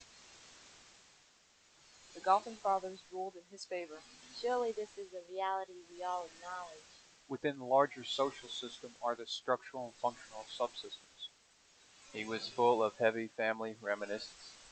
These audio examples compare single channel noise reduction using Martin's Minimum Statistics [1], the bias compensated MMSE approach [2], and the proposed SPP approach.
modulated white audio audio audio